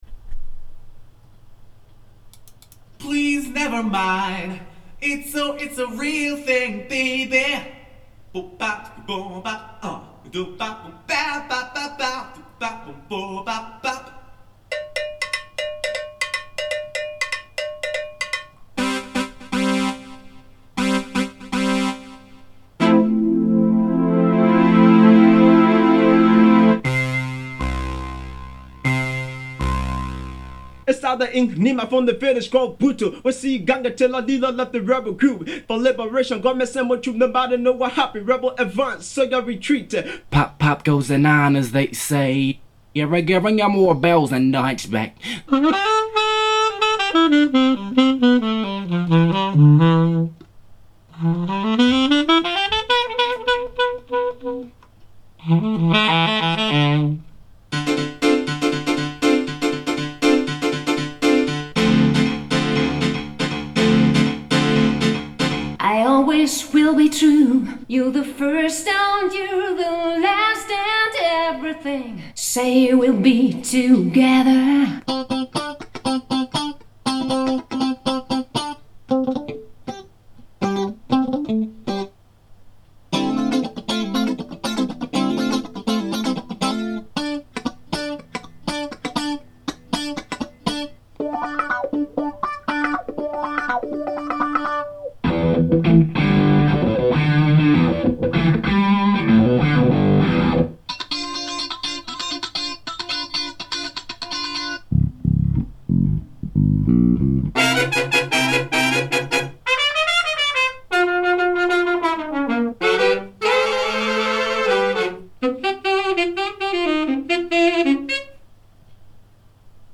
これは内蔵マイクでゲインH．24bit/44.1kHzで録音したものをノーマライズしてmp3化．
最初のマウスクリックの音でスピーカーの音が割と小さいことがわかるはず．
Testing Internal Mic Gain-H Stereo (normalized, 24to16bit).mp3